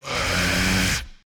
burer_aggressive_1.ogg